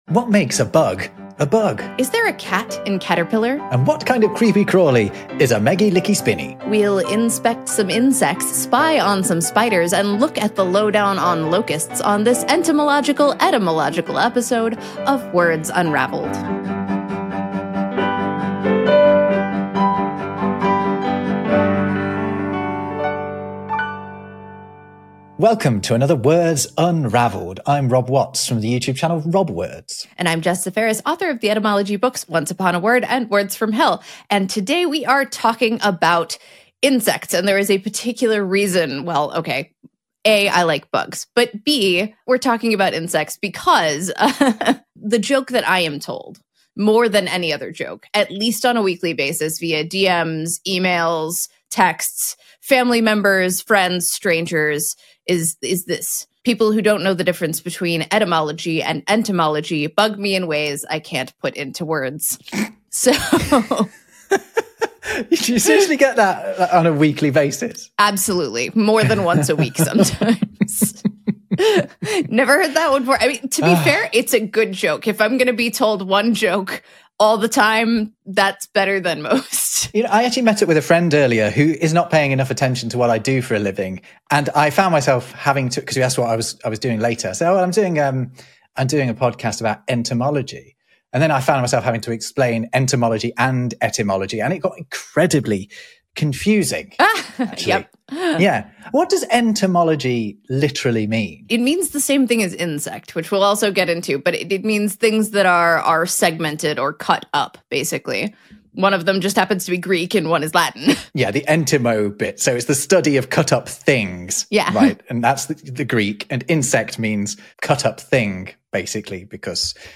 Wednesday Service